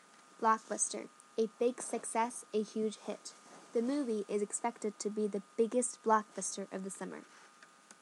英語ネイティブによる発音は下記のリンクから聞くことができます。